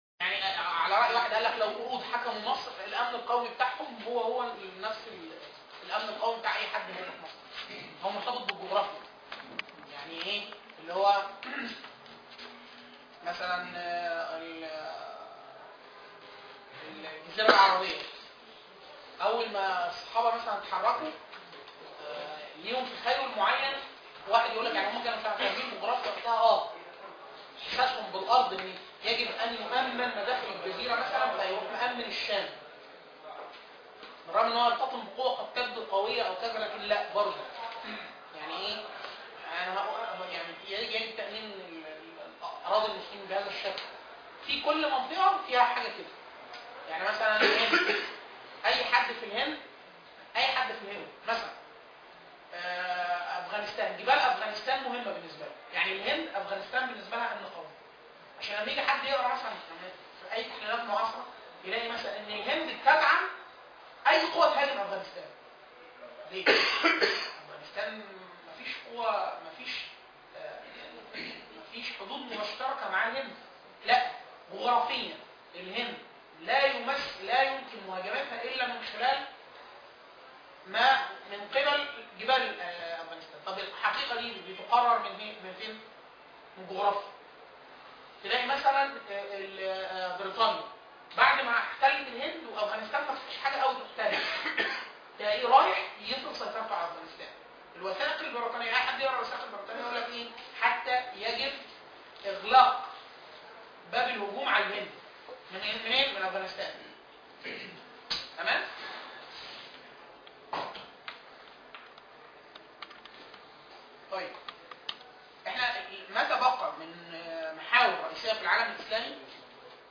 دورة التأسيس المعرفي (المحاضرة السابعة